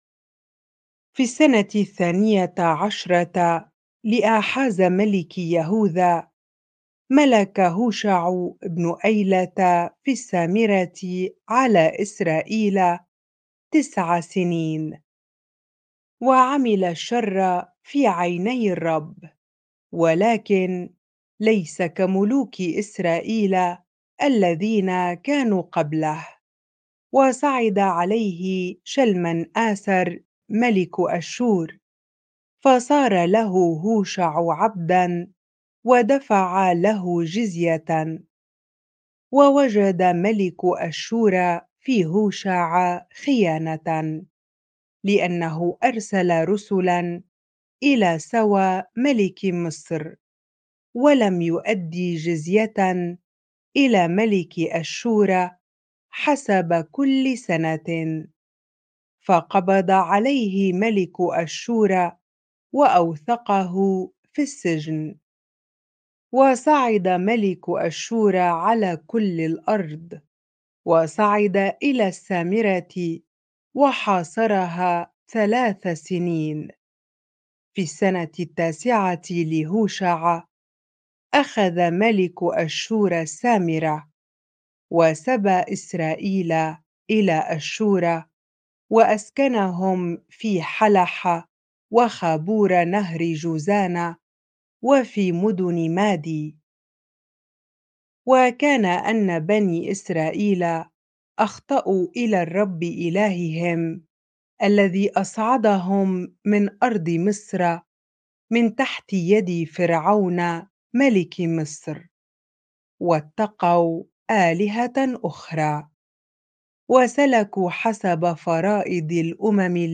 bible-reading-2 Kings 17 ar